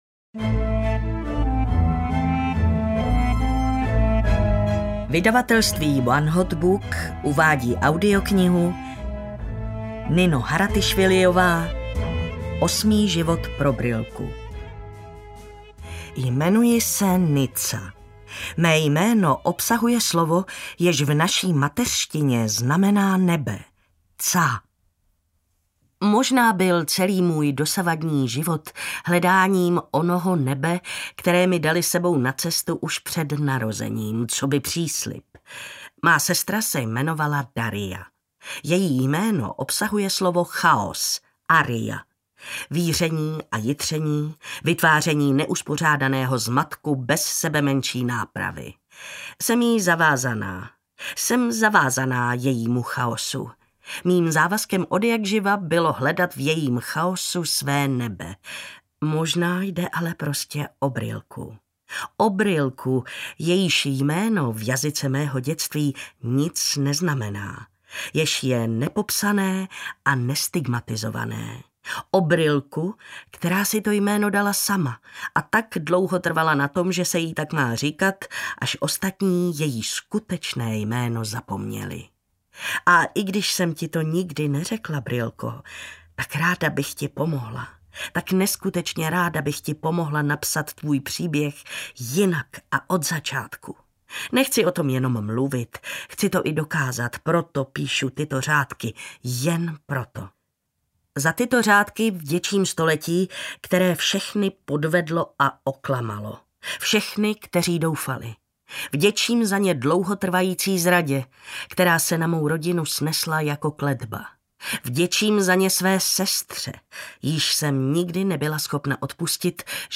Osmý život (pro Brilku) audiokniha
Ukázka z knihy